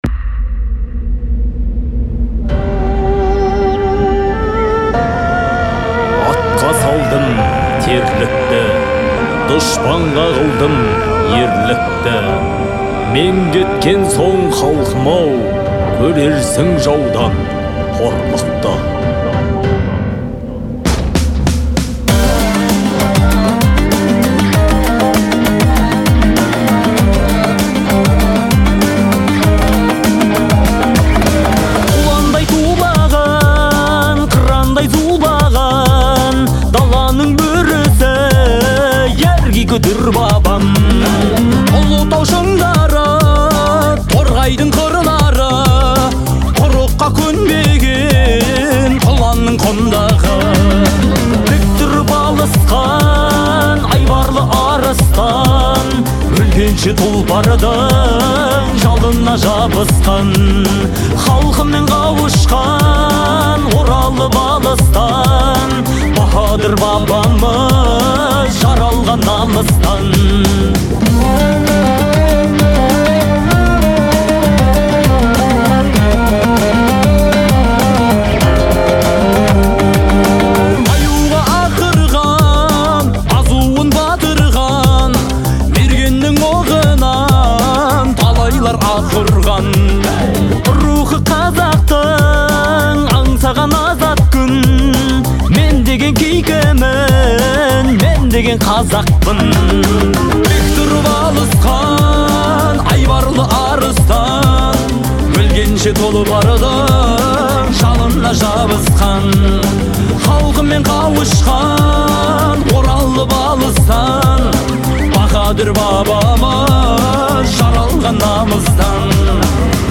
относится к жанру казахского фолка
наполнена патриотическим настроением